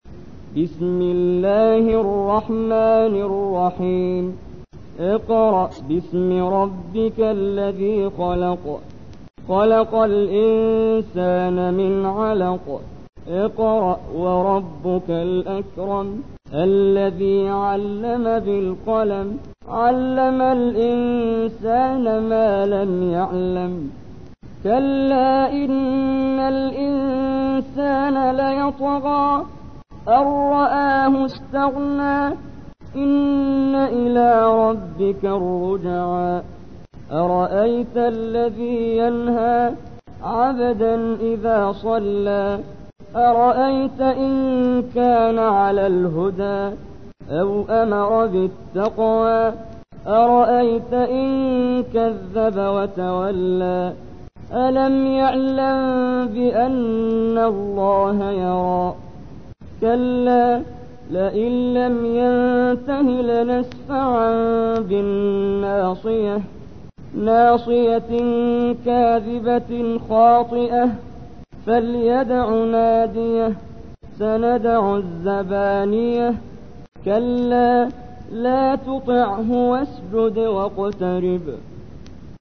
تحميل : 96. سورة العلق / القارئ محمد جبريل / القرآن الكريم / موقع يا حسين